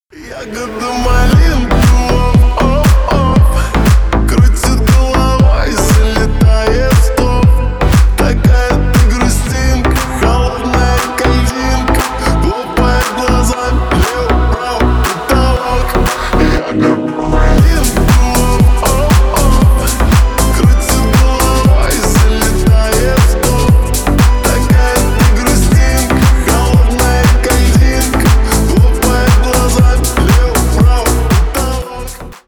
• Качество: 320, Stereo
мужской голос
remix
Electronic
Club House
Стиль: club house